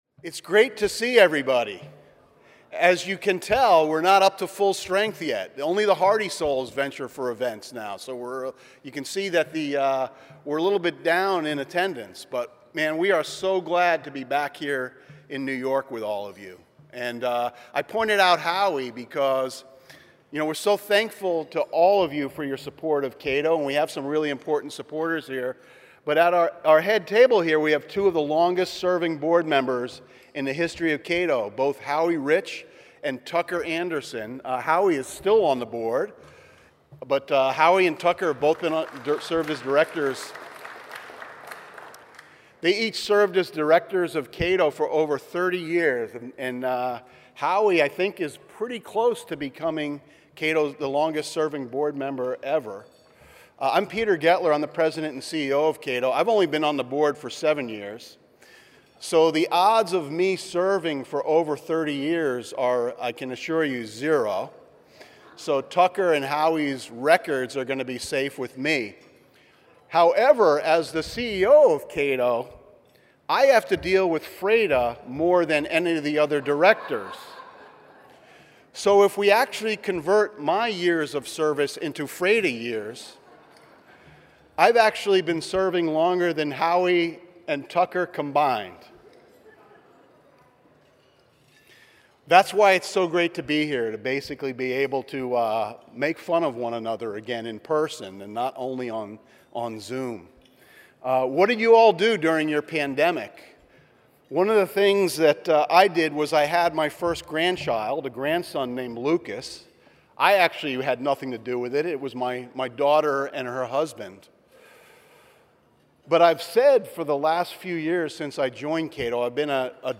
Welcoming Remarks and Lunch:
A conversation with Mark Calabria